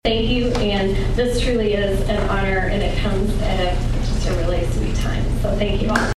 This week’s Carmi Kiwanis Club meeting was highlighted by the announcement of the club’s Educator of the year and Support Staff of the year awards.